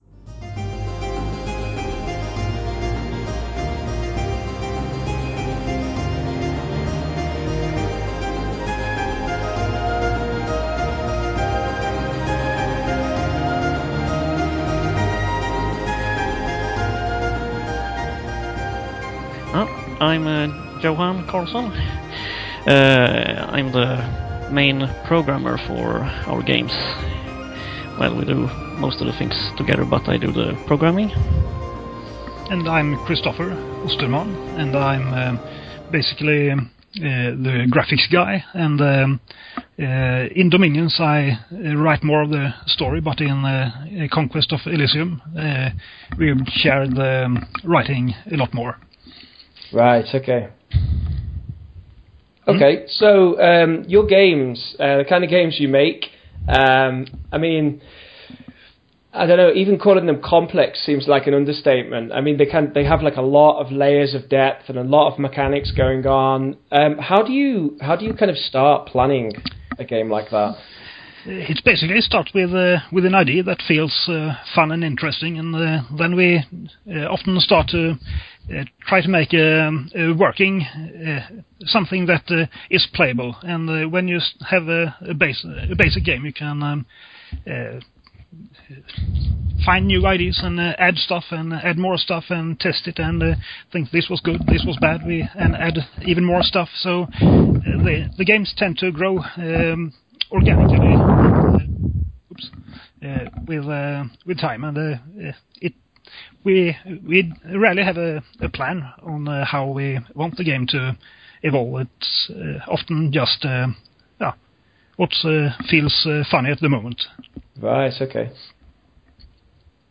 Interviewing Illwinter: The Creators of Dominions and Conquest of Elysium